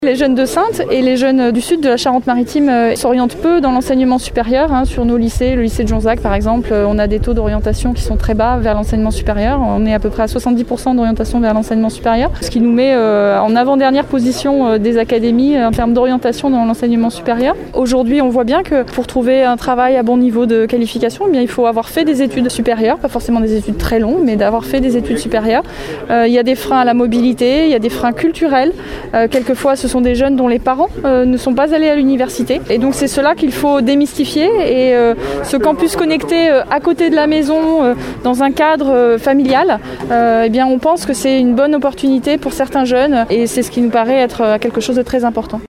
Ce matin, lors de la visite du Campus connecté de Saintes.
De quoi pallier les difficultés d’accès à l’enseignement supérieur sur le territoire, comme l’explique Bénédicte Robert, rectrice de l’Académie de Poitiers, qui s’est rendue sur place ce matin :